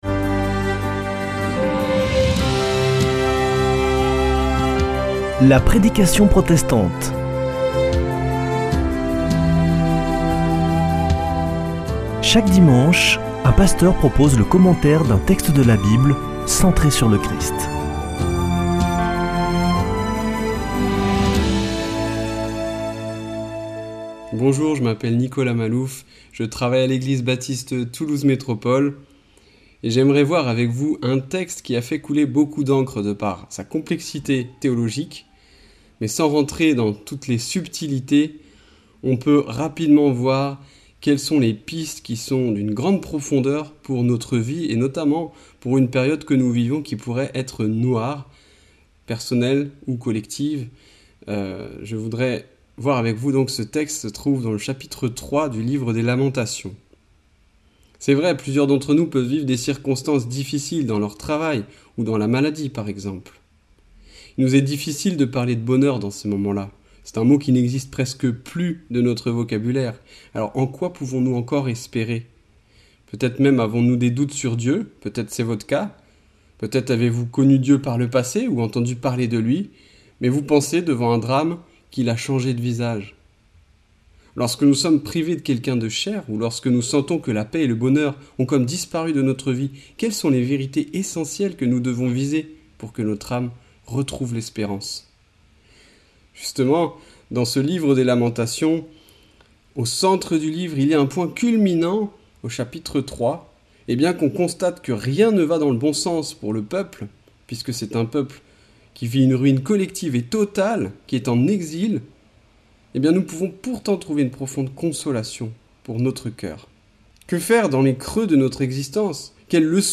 Accueil \ Emissions \ Foi \ Formation \ La prédication protestante \ Dieu se soucie-t-il de mes larmes ?